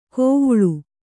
♪ kōvuḷu